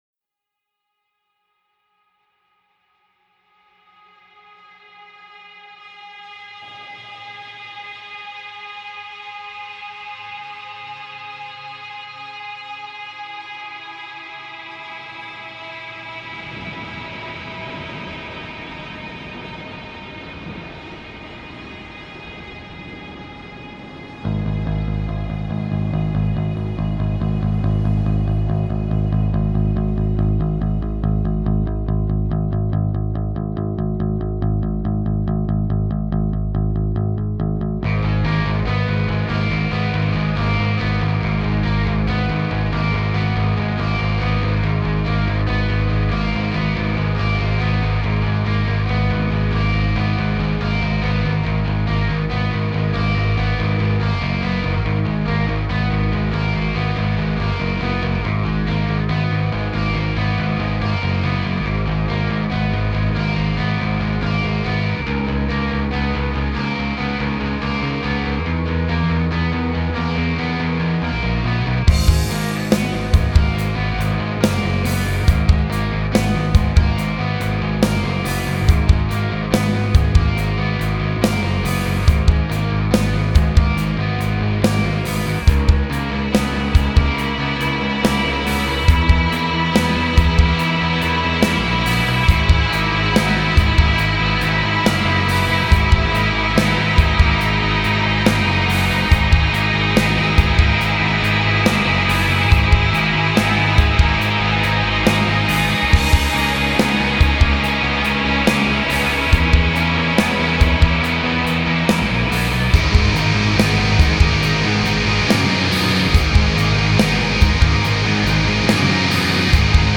black metal
atmospheric post black metal
چقدر این ضرب درام دوست داشتنیه...